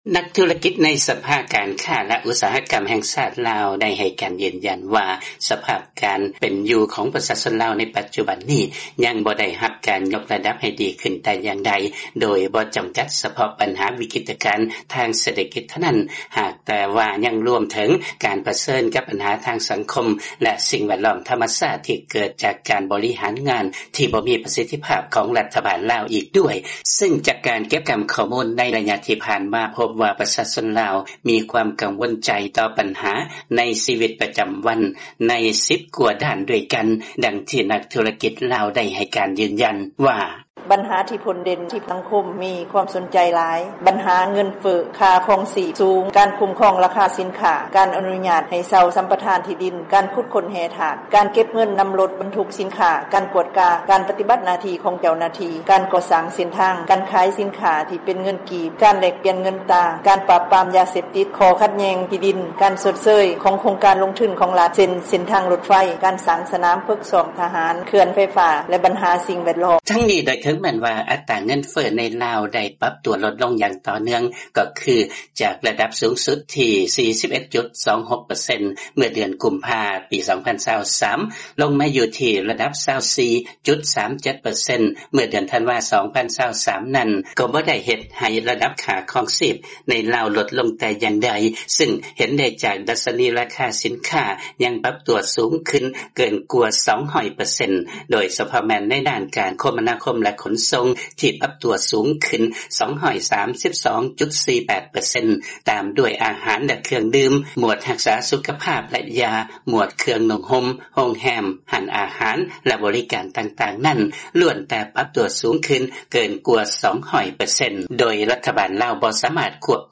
ຟັງລາຍງານ ປະຊາຊົນລາວ ຍັງຈະຕ້ອງປະເຊີນກັບວິກິດການທາງເສດຖະກິດ ແລະສັງຄົມ ຍ້ອນການແກ້ໄຂບັນຫາຍັງບໍ່ມີປະສິດທິພາບ